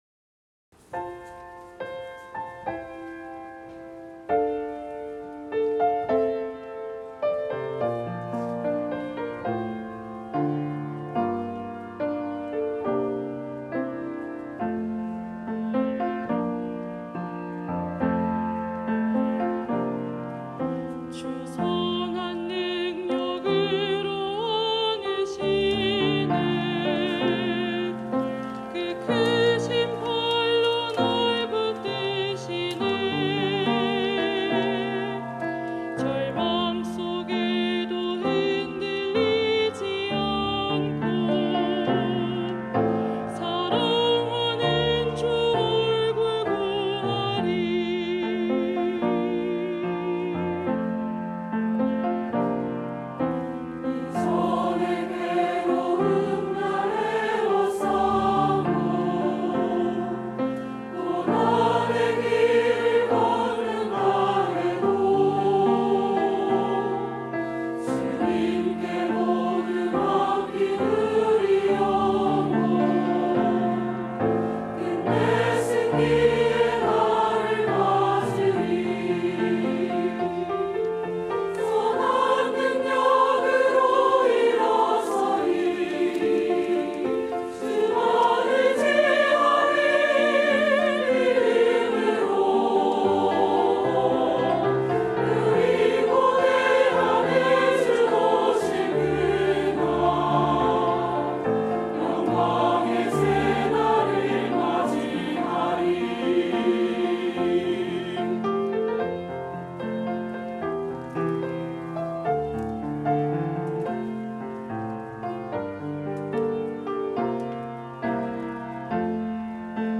3부 찬양대(~2024)